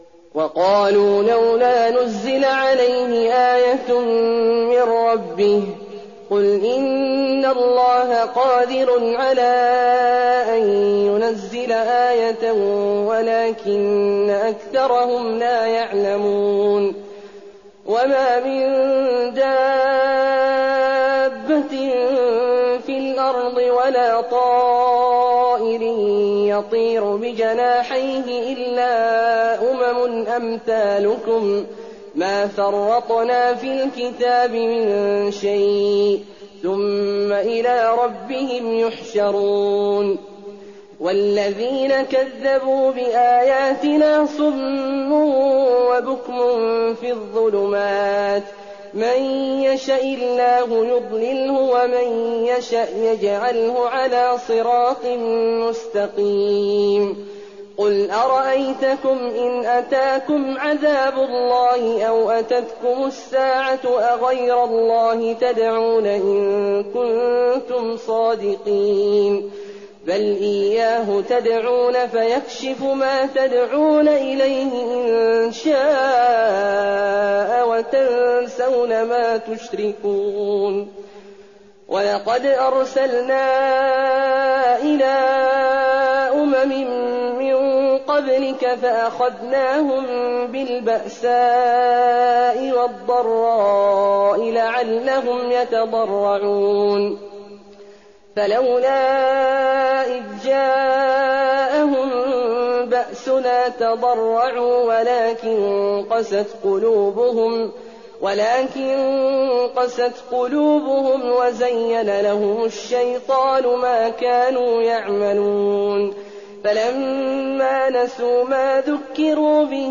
تراويح الليلة السابعة رمضان 1419هـ من سورة الأنعام (37-110) Taraweeh 7th night Ramadan 1419H from Surah Al-An’aam > تراويح الحرم النبوي عام 1419 🕌 > التراويح - تلاوات الحرمين